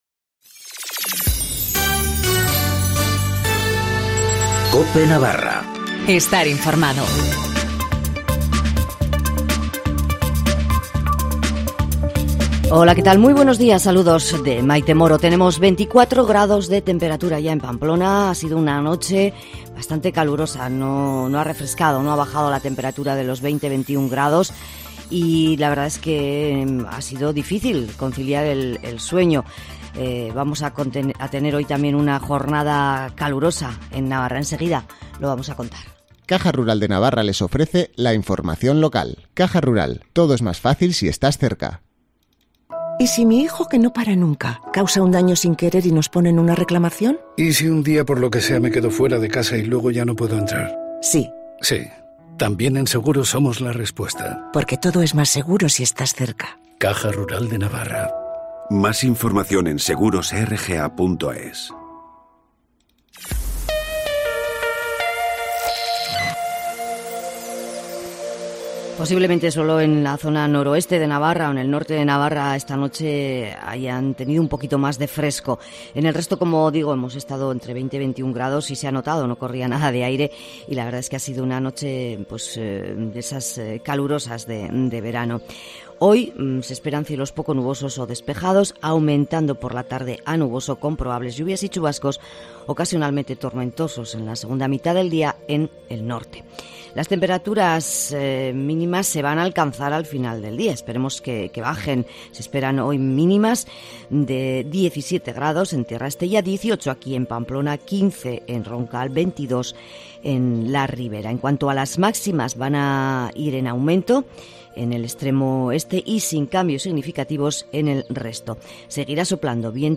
Informativo matinal del 9 de agosto